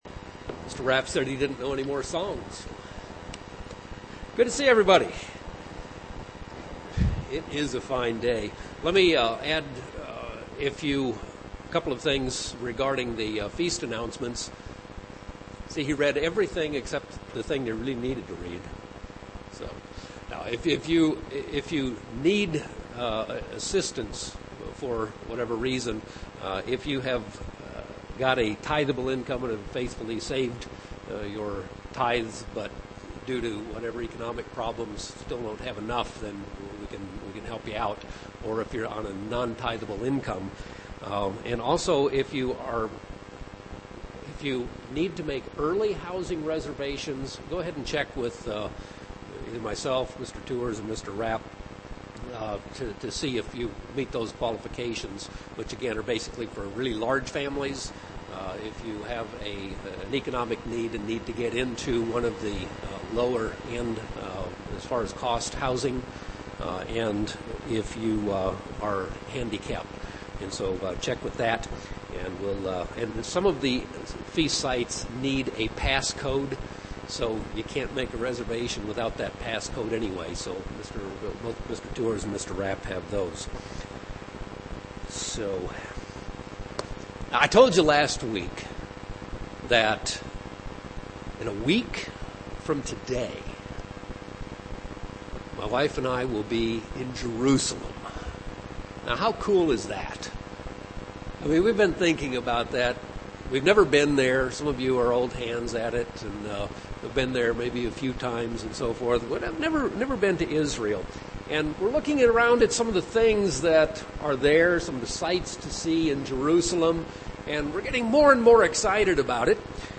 Given in Phoenix East, AZ
See Powerpoint UCG Sermon Studying the bible?